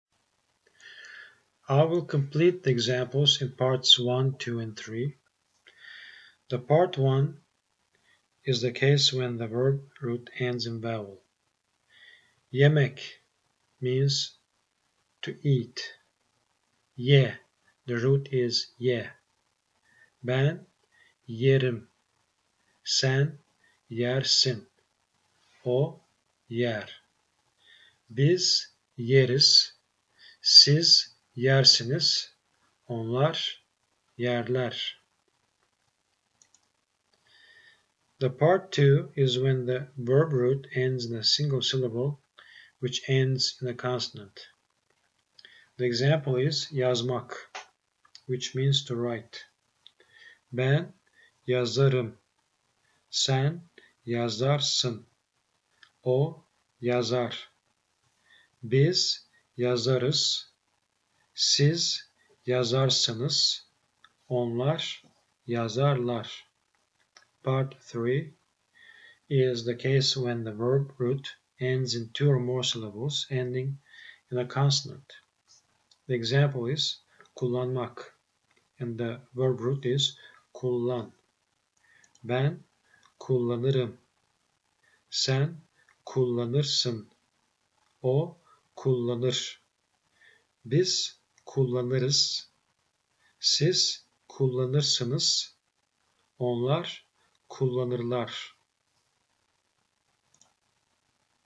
First try to guess how these words are pronounced, then click on the link at the bottom and listen.
Click on the link to listen to the mini lesson on Present Tense